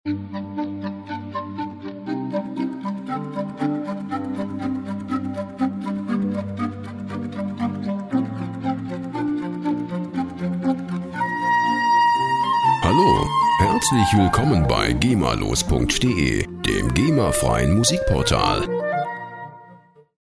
Klarinettenquartett